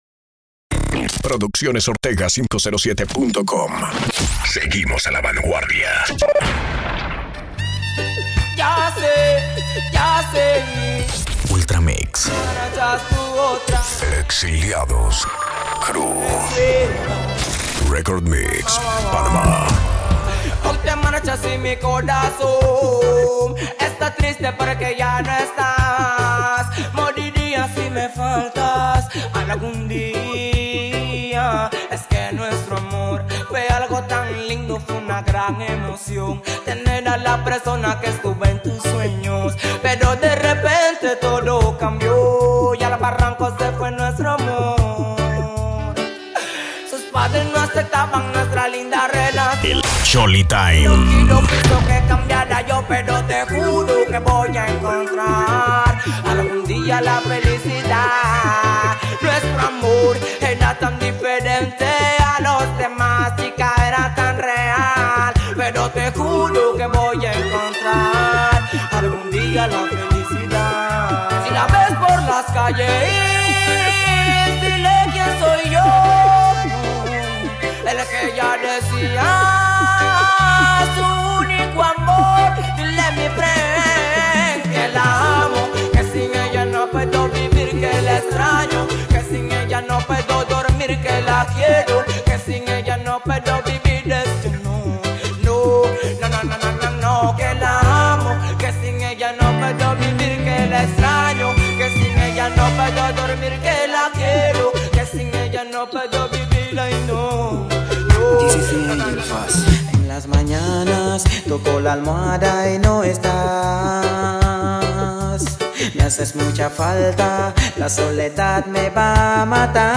Mixes, Reggae